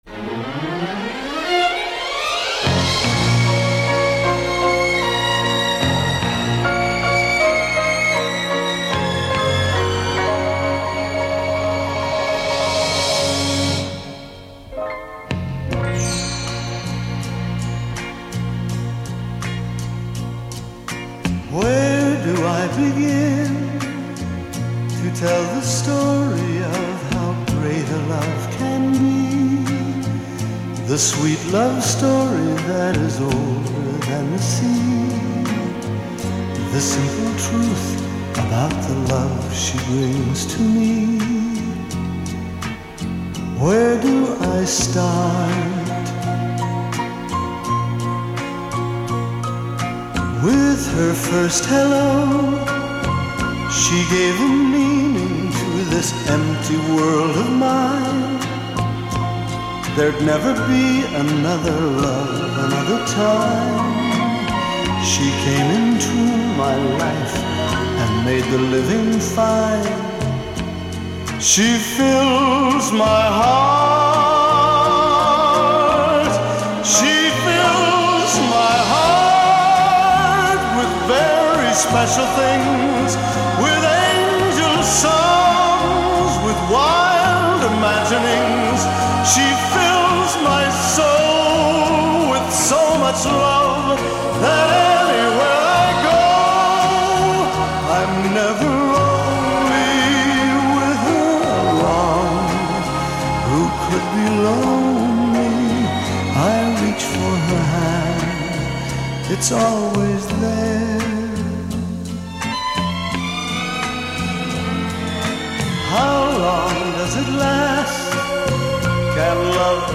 قدیمی جذاب و عاشقانه